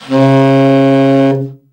BRA_TEN SFT    4.wav